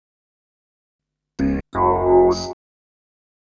Techno / Voice / VOICEFX168_TEKNO_140_X_SC2.wav
1 channel